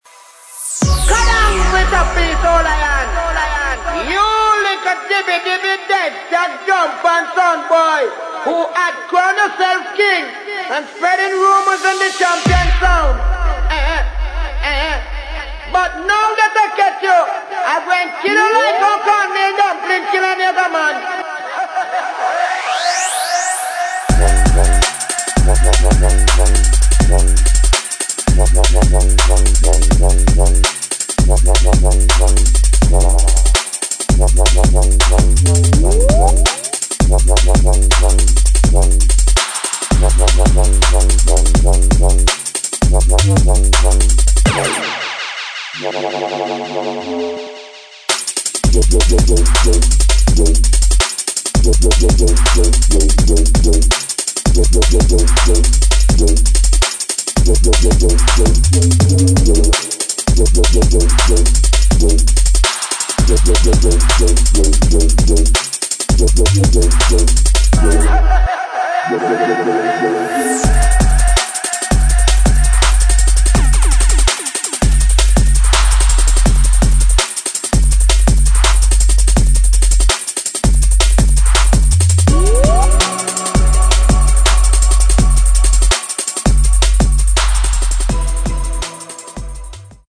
[ DRUM'N'BASS / JUNGLE / JUKE ]